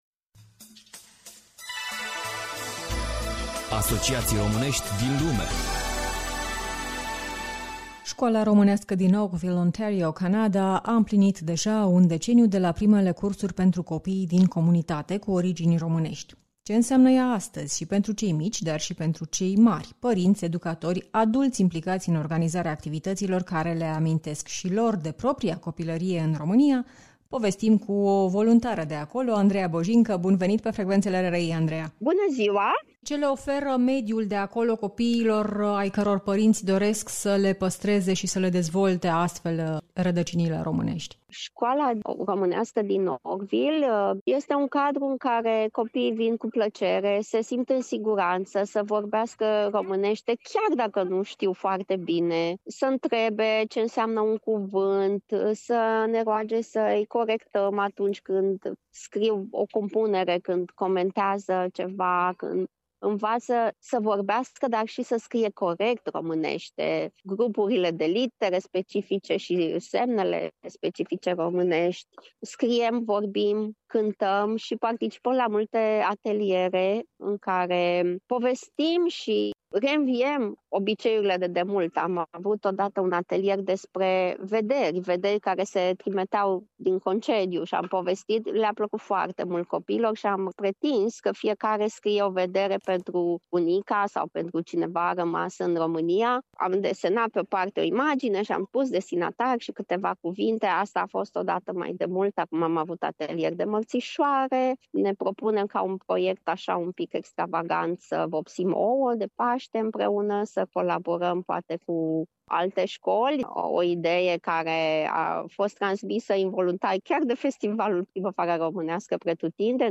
Școala românească din Oakville, Ontario, Canada. Interviu